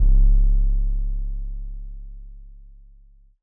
Slide C#.wav